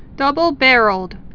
(dŭbəl-bărəld)